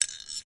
拨浪鼓的种类
描述：拨浪鼓金属铿锵声
Tag: 金属 拨浪鼓